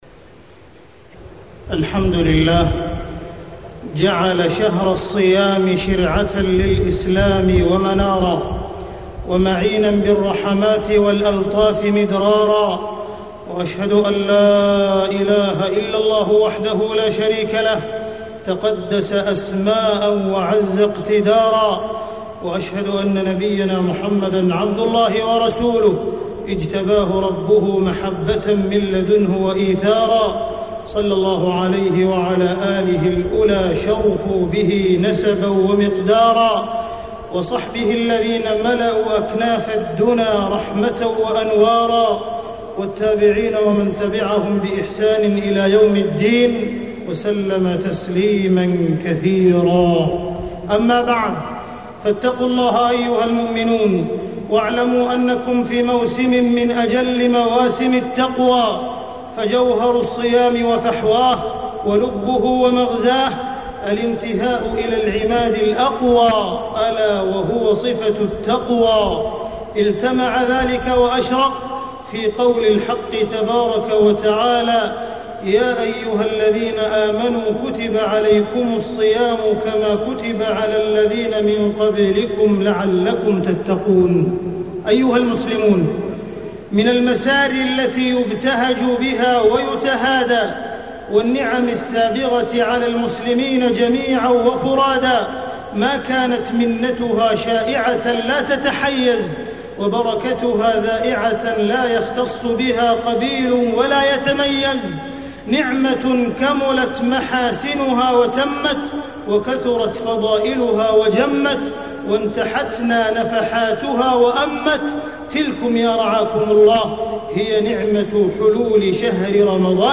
تاريخ النشر ٦ رمضان ١٤٢٧ هـ المكان: المسجد الحرام الشيخ: معالي الشيخ أ.د. عبدالرحمن بن عبدالعزيز السديس معالي الشيخ أ.د. عبدالرحمن بن عبدالعزيز السديس الله أكبر هل رمضان